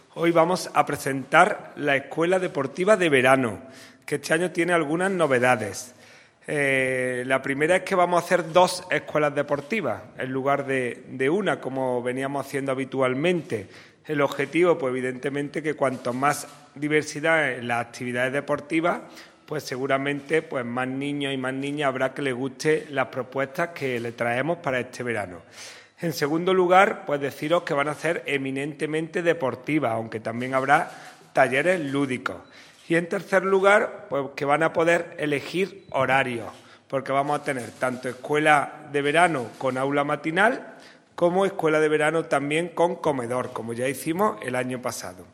El teniente de alcalde delegado de Deportes, Juan Rosas, ha presentado hoy en rueda de prensa las Escuelas Deportivas de Verano 2022, iniciativa que promueve el Área de Deportes del Ayuntamiento de Antequera con el objetivo de facilitar la conciliación familiar y laboral durante el mes de julio, época ya de vacaciones en los colegios.
Cortes de voz